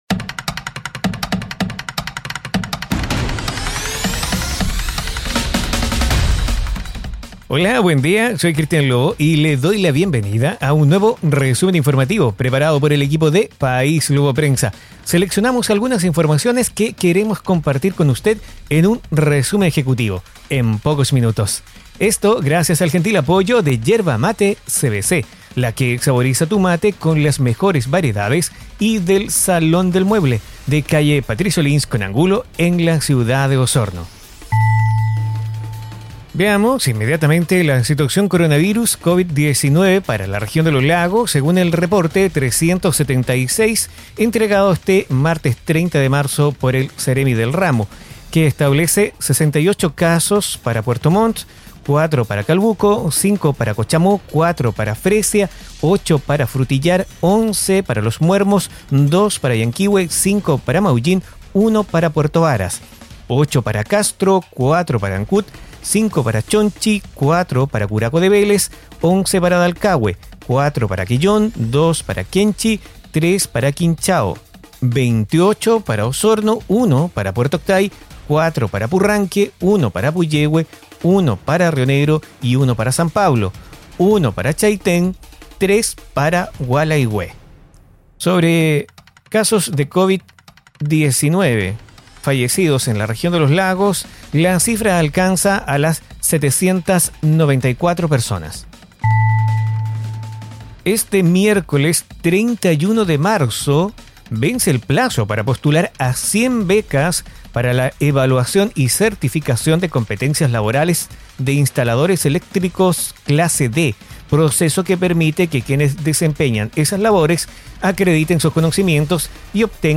Informaciones enfocadas en la Región de Los Lagos. Difundido en radios asociadas.